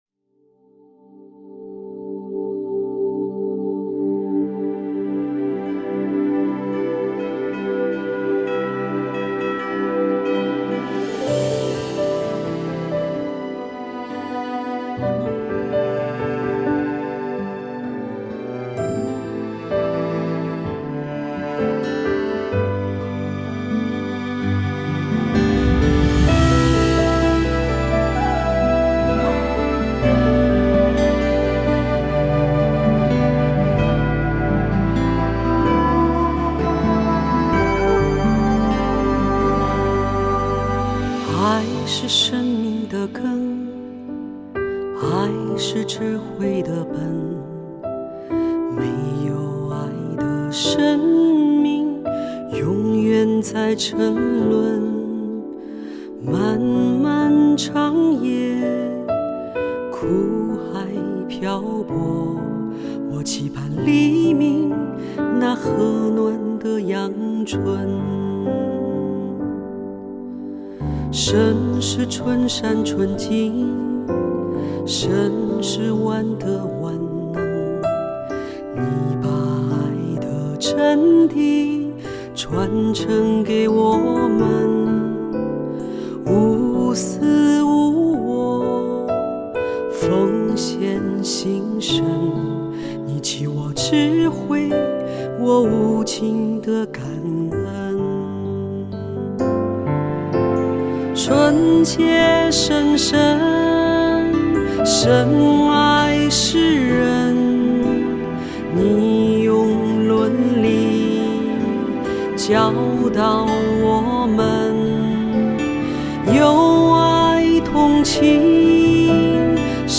Bai Hat Than Ai The Nhan - hat tieng Hoa.mp3